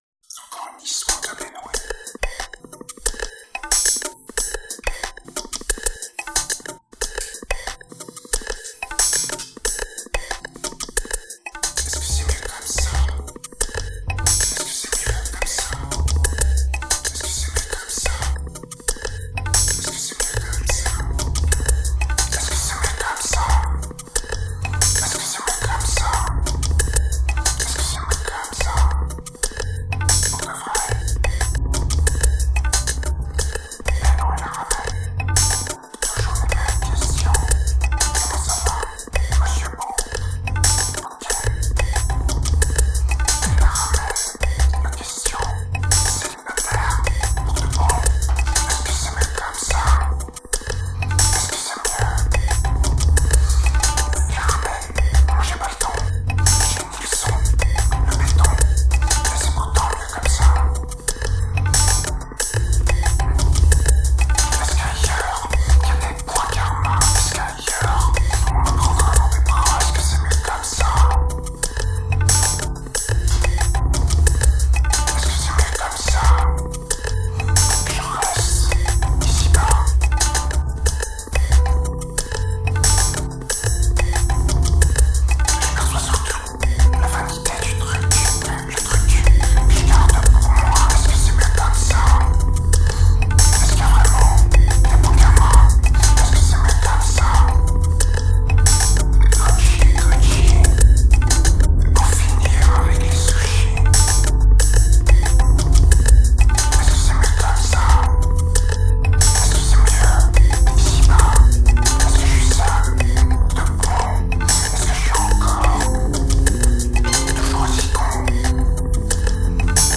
a dark album, fast and poorly recorded during late 2007